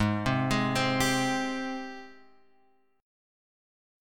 G#M7 chord {4 3 x 5 4 3} chord